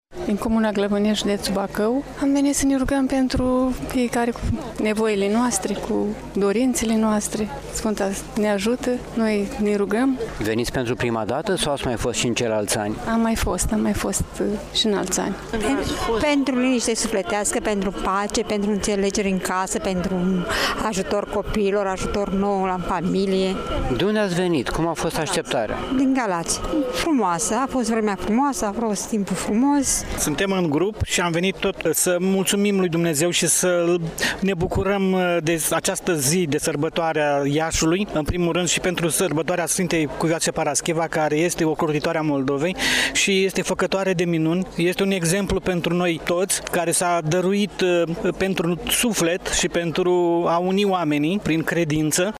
12-oct-rdj-17-vox-pelerini.mp3